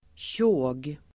Ladda ner uttalet
Uttal: [tjå:g]